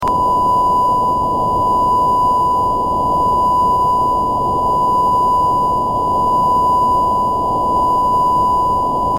Raw I/Q of pulse tone